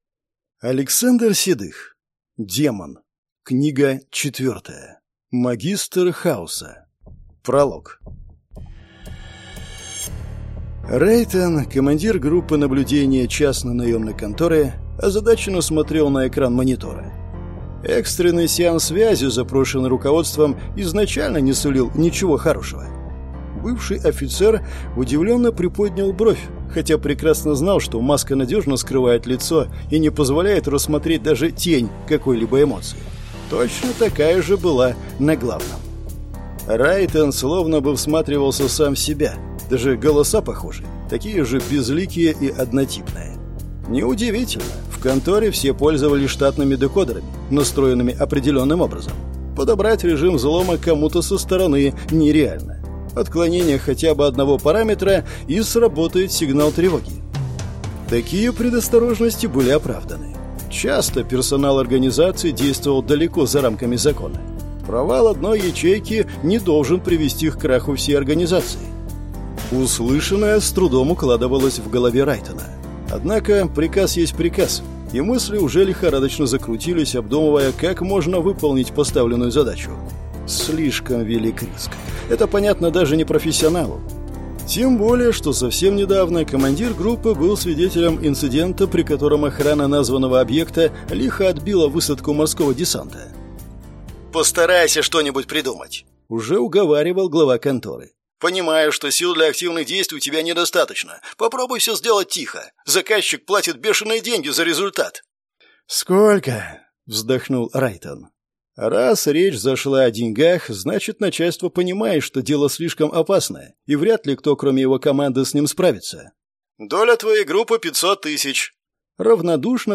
Аудиокнига Магистр хаоса | Библиотека аудиокниг